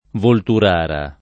vai all'elenco alfabetico delle voci ingrandisci il carattere 100% rimpicciolisci il carattere stampa invia tramite posta elettronica codividi su Facebook Volturara [ voltur # ra ] top. — due comuni: V. Irpina (Camp.); V. Appula (Puglia)